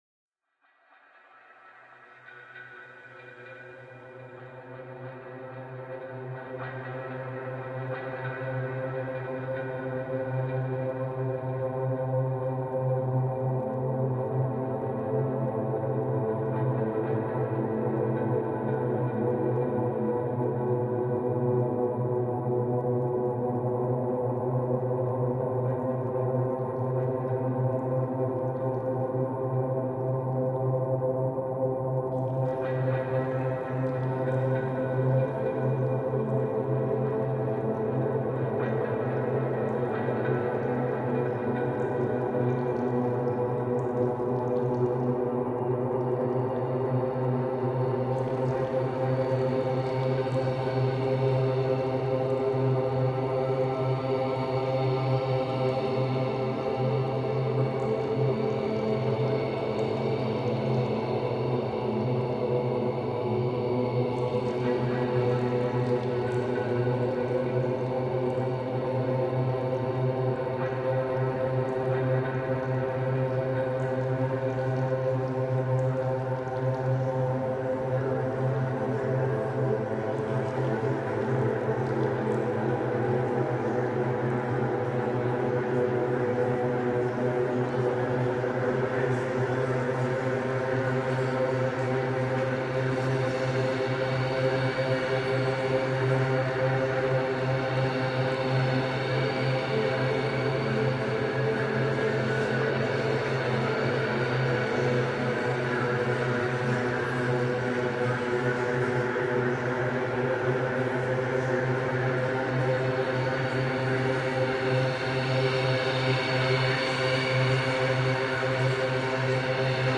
experimental ambient